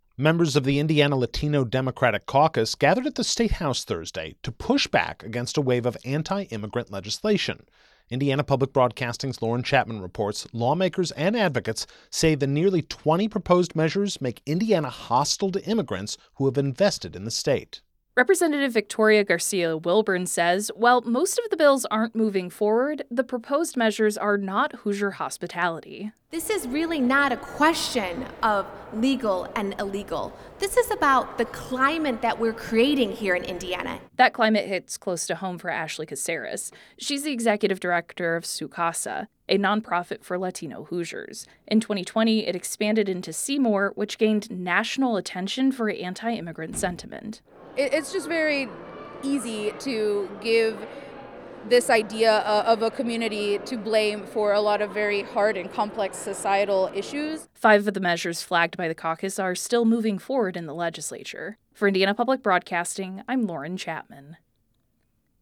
correctlatino-caucus-rally-web.mp3